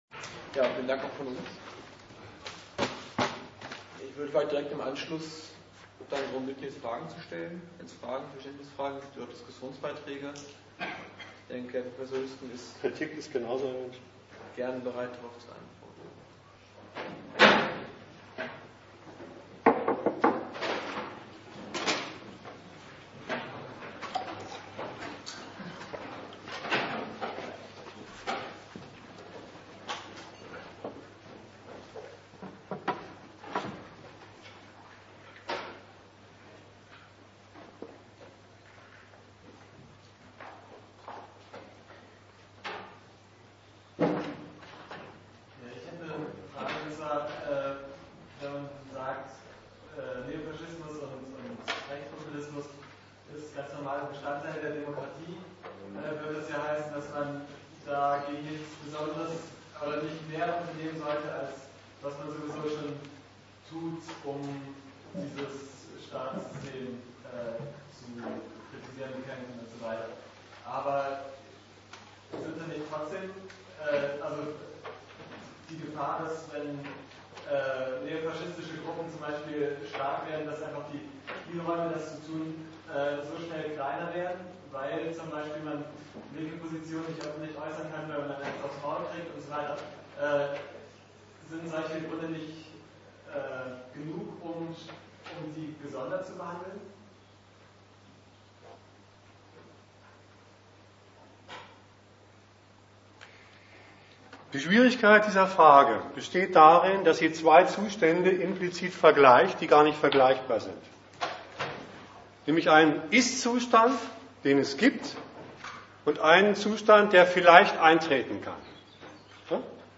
Die Veranstaltung fand statt unter dem Titel: Demokratie, Rechtspopulismus, Neofaschismus